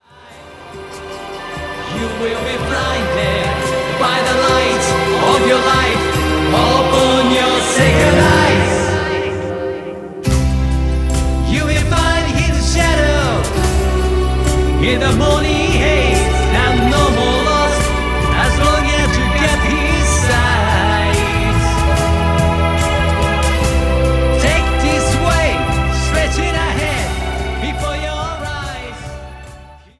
ジャンル Progressive
Rock
アンビエント
シンフォニック系